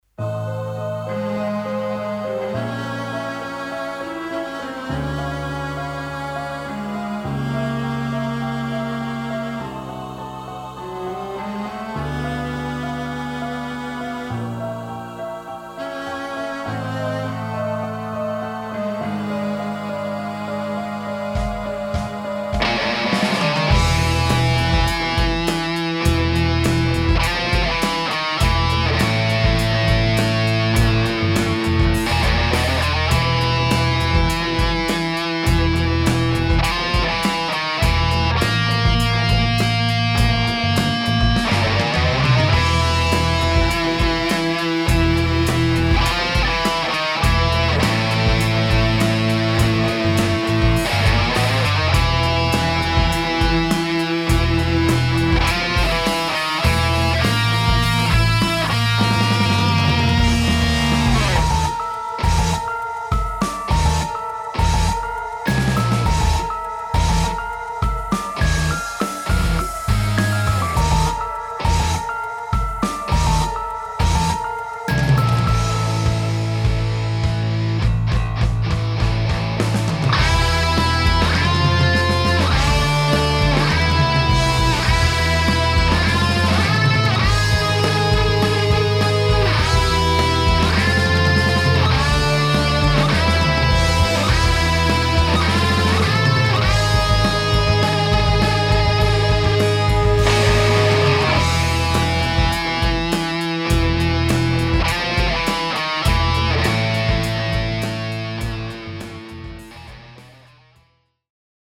フリーBGM イベントシーン 緊張感